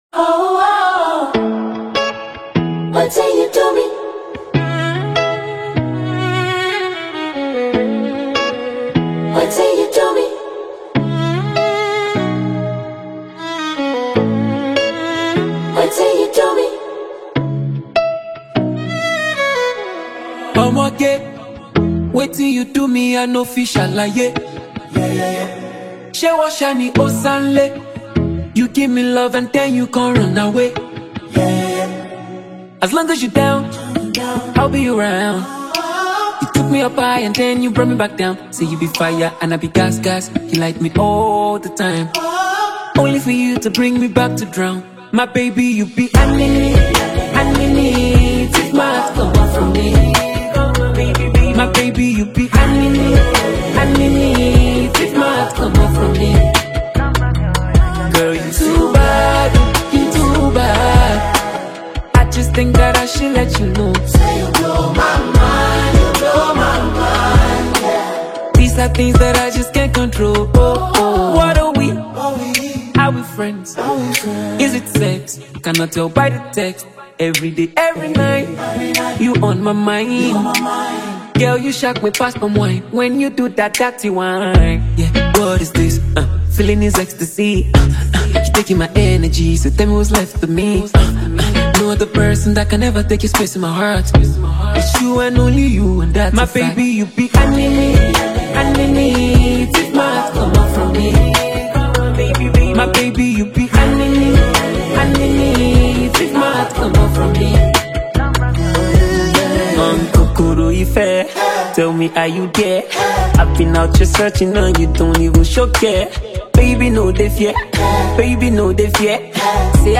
metaphorical love joint
Nigerian Afro Hip-hop act